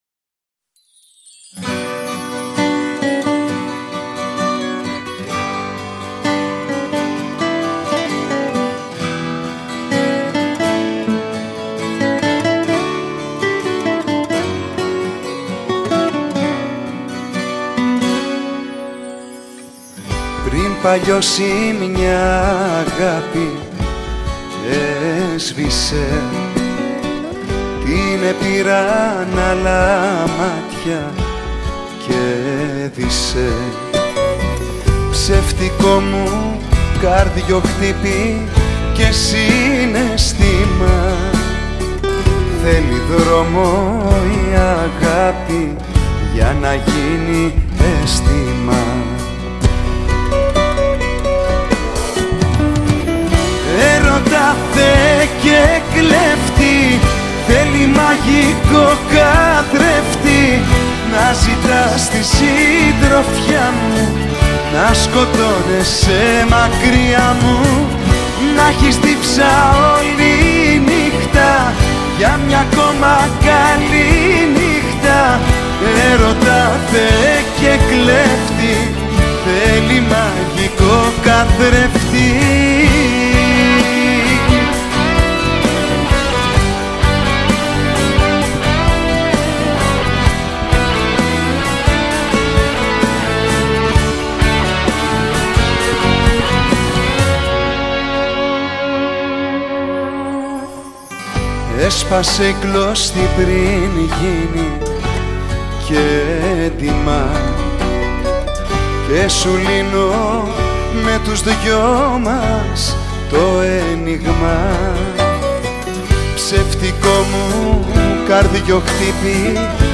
Жанр: ΛΑΪΚΆ / ΣΥΓΧΡΟΝΗ